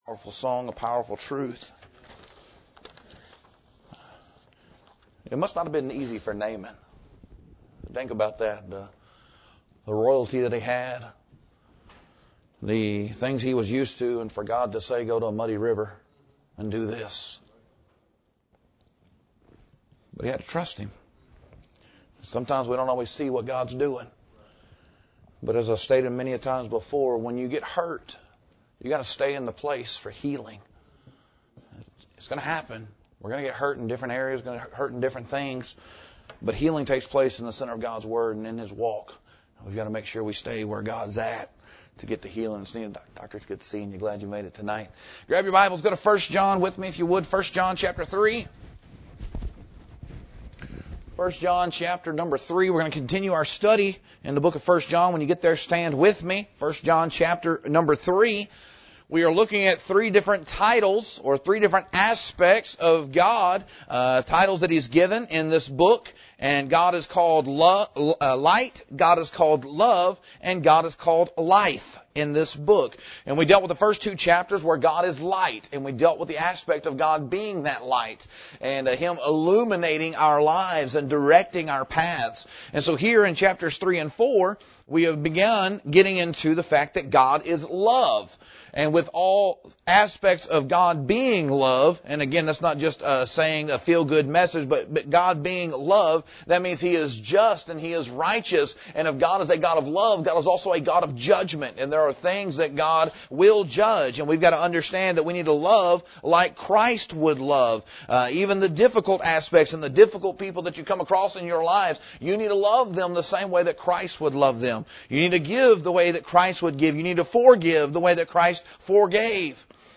Quote from Preacher